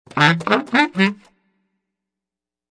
Descarga de Sonidos mp3 Gratis: saxofon 17.